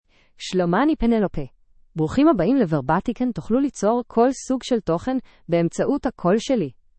PenelopeFemale Hebrew AI voice
Penelope is a female AI voice for Hebrew (Israel).
Voice: PenelopeGender: FemaleLanguage: Hebrew (Israel)ID: penelope-he-il
Voice sample
Listen to Penelope's female Hebrew voice.
Penelope delivers clear pronunciation with authentic Israel Hebrew intonation, making your content sound professionally produced.